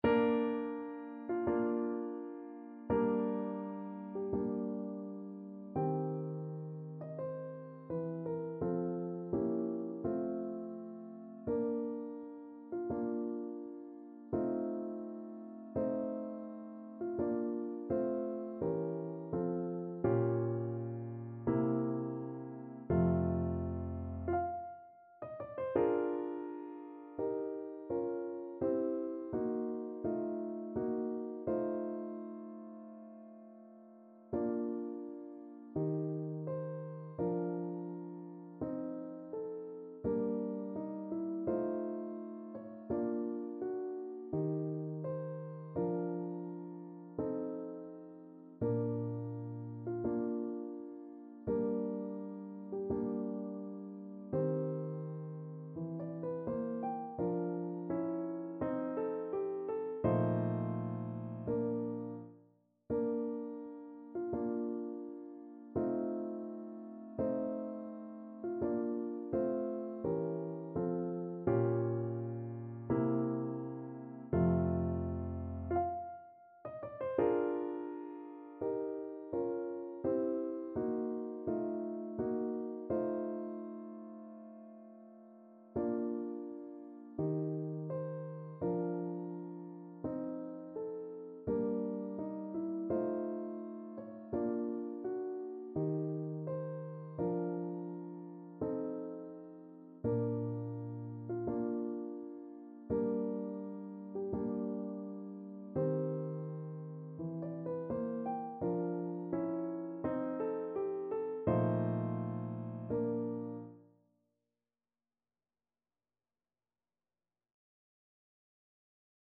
Bb major (Sounding Pitch) (View more Bb major Music for Cello )
2/4 (View more 2/4 Music)
~ = 42 Sehr langsam
Classical (View more Classical Cello Music)